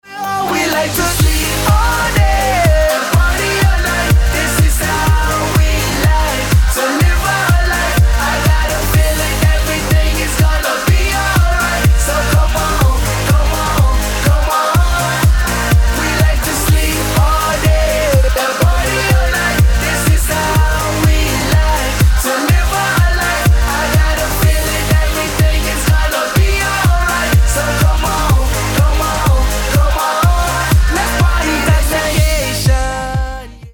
spevák